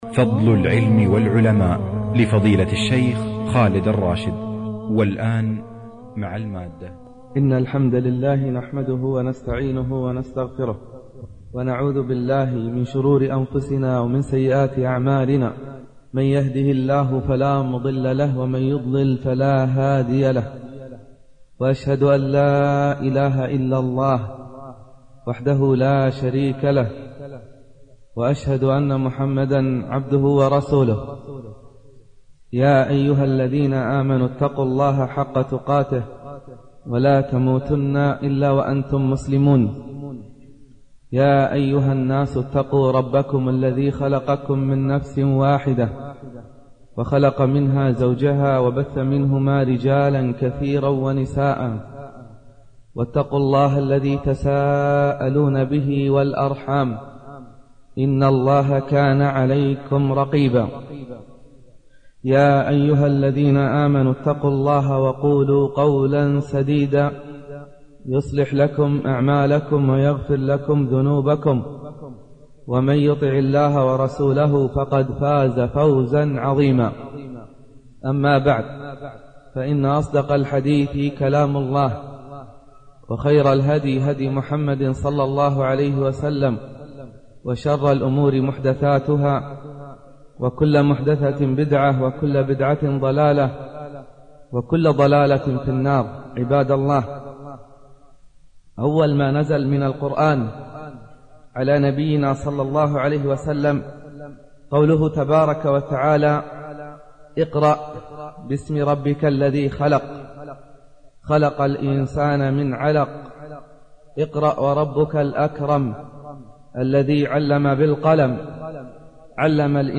الخطبة تبدأ بالتنبيه إلى أن أول ما نزل من القرآن يرسخ قيمة العلم، وأن العلم هو الأساس لترسيخ العقيدة الصحيحة.